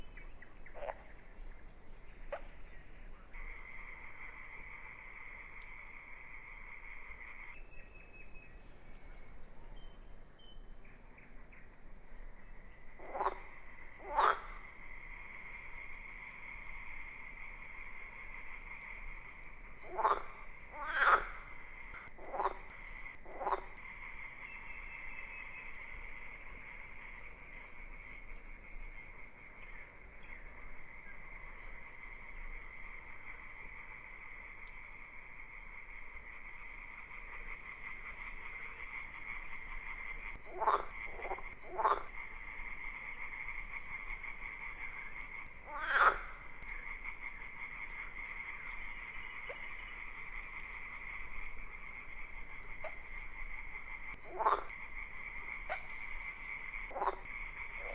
beyond/Assets/Sounds/Nature/frogs.ogg at unity6
frogs.ogg